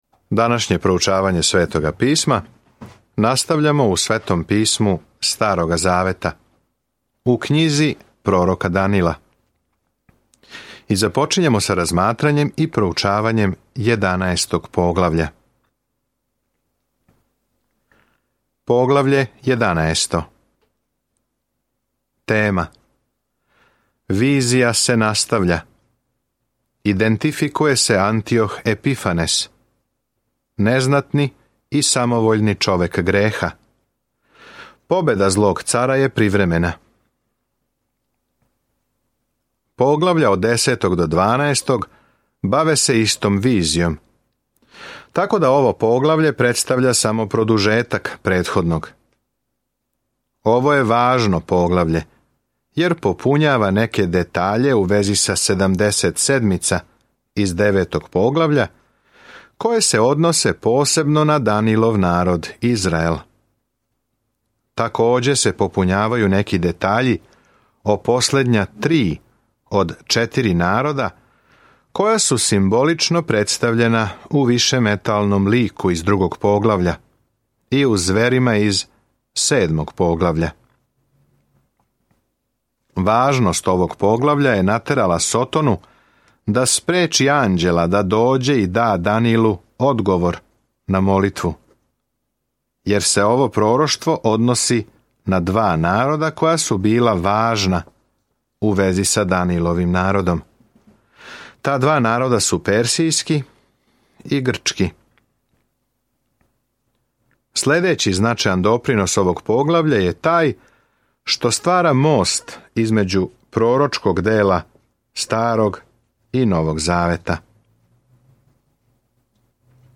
Sveto Pismo Knjiga proroka Danila 11:1-16 Dan 25 Započni ovaj plan Dan 27 O ovom planu Књига пророка Данила је и биографија човека који је веровао Богу и пророчка визија о томе ко ће на крају завладати светом. Свакодневно путујте кроз Данила док слушате аудио студију и читате одабране стихове из Божје речи.